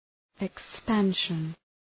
Προφορά
{ık’spænʃən}